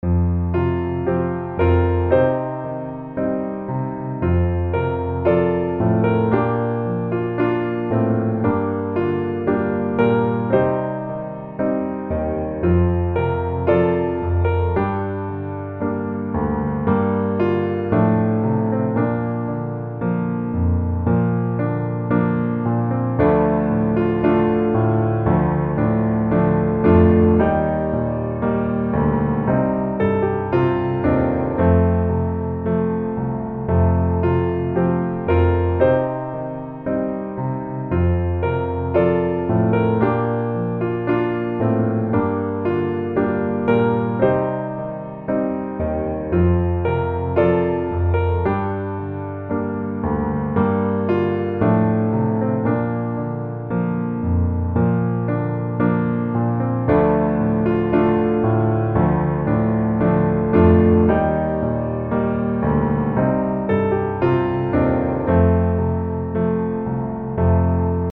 G Mineur